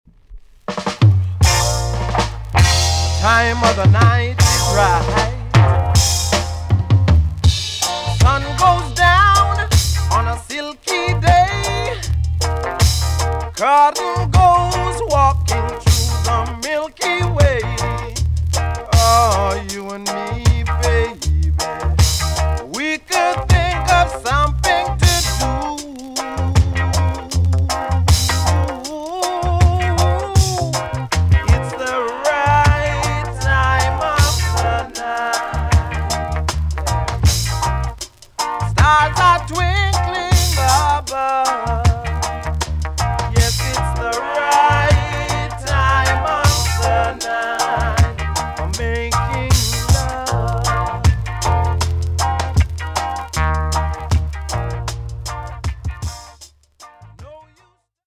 TOP >DISCO45 >VINTAGE , OLDIES , REGGAE
EX- 音はキレイです。
NICE VOCAL TUNE!!